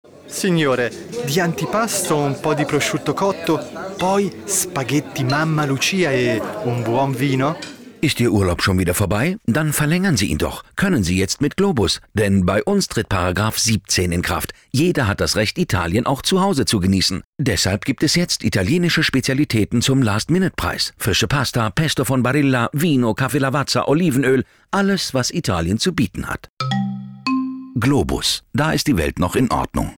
Sprecher italienisch.
Kein Dialekt
Sprechprobe: Sonstiges (Muttersprache):